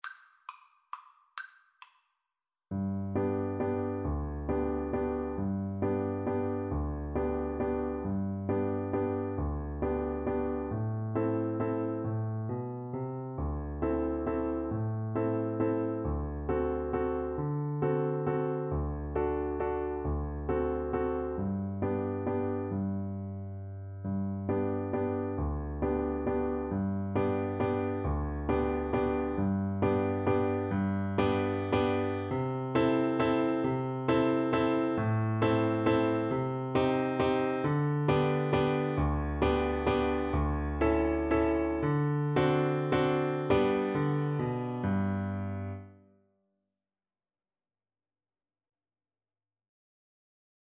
Cello version
Waltz .=45
3/4 (View more 3/4 Music)
Classical (View more Classical Cello Music)